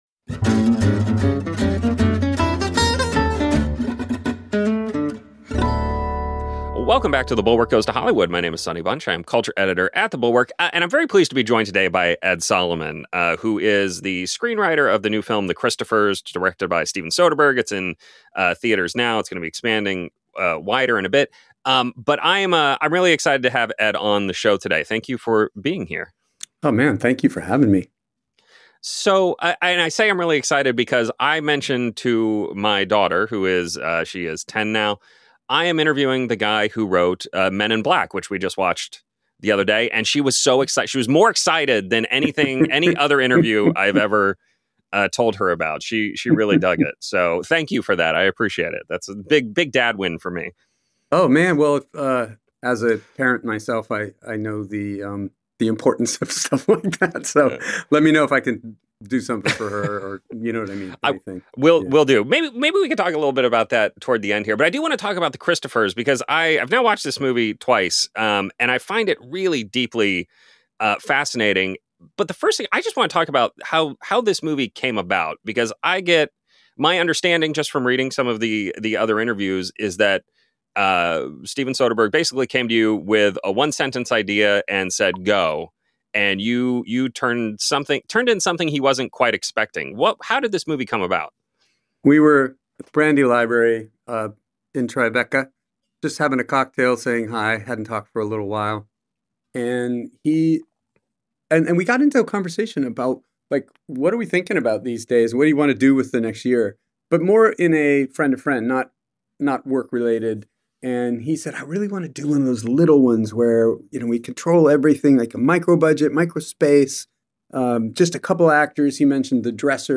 I’m joined on this week’s episode by Ed Solomon, the writer of the new Steven Soderbergh feature, The Christophers, which is in limited release this weekend and going wider next. It’s the story of an aging artist, played by Ian McKellen, and his new assistant, played by Michaela Cole, who has been hired by the artist’s son and daughter to forge a series of paintings from an earlier, more successful period in his career.